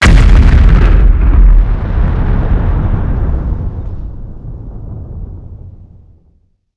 Random Explosion WAV
Subject description: good for a nuke exp or bigger.
This is the planet exploding sound from EAW.
You could use it as the sound of a firing artillery.
It sounds exactly like the "building crumble" sound in TS...or was it TD?
It was the TD or even RA1 building crumble I think.